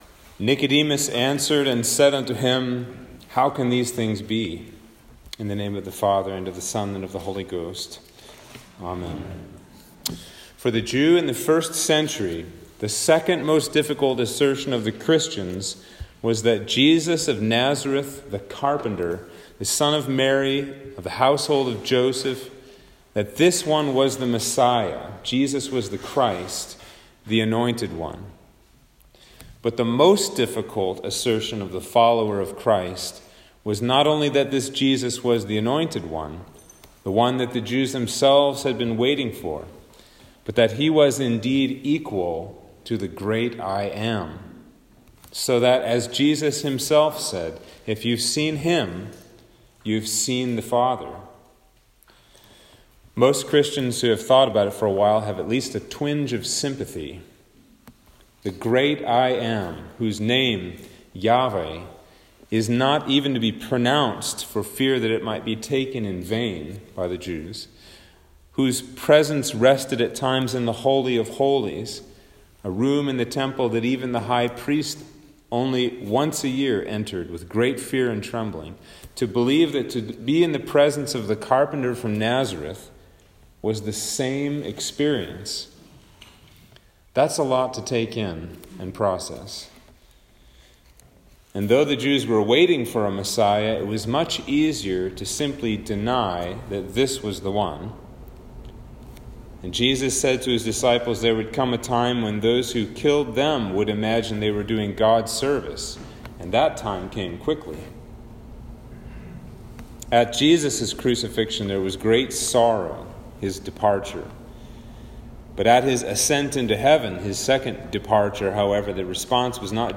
Sermon for Trinity Sunday
Sermon-for-Trinity-Sunday-2021.m4a